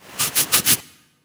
scratch_1.wav